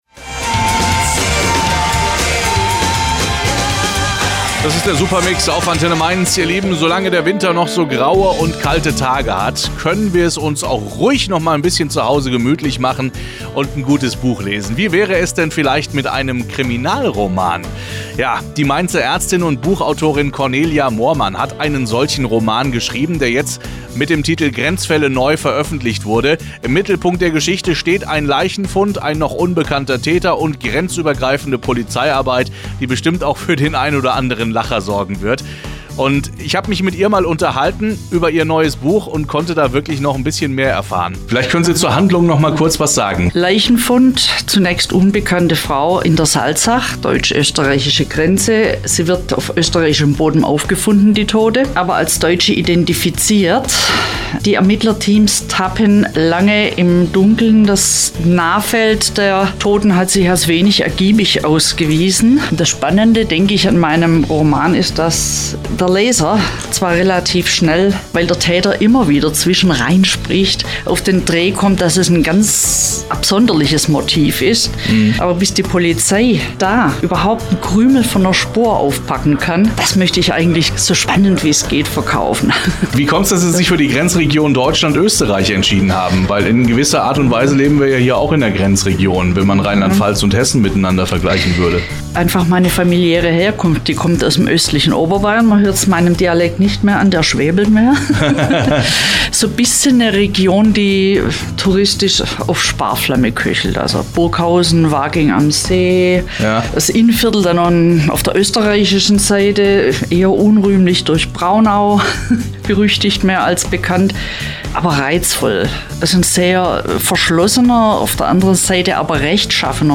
Mitschnitt Buchtalk